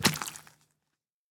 sounds / mob / drowned / step1.ogg
step1.ogg